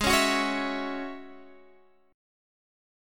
Ab7sus4#5 chord